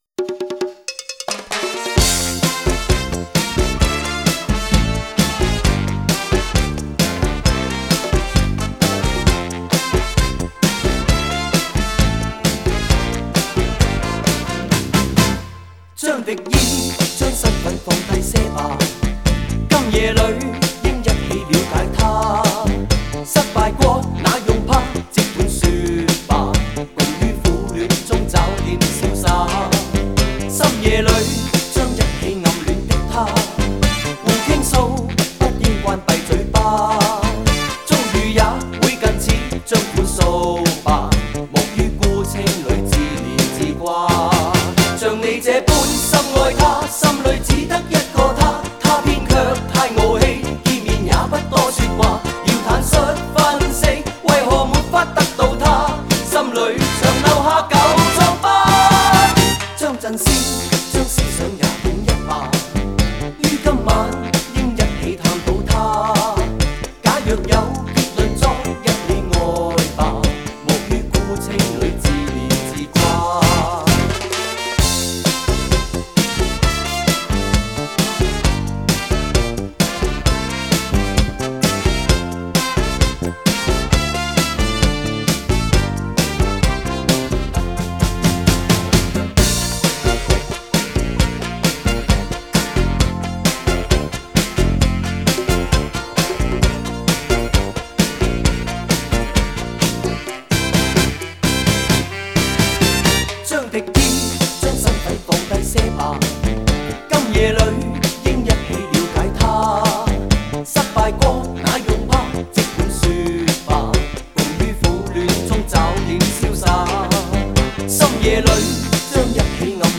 国风 收藏 下载